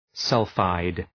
Shkrimi fonetik {‘sʌlfaıd}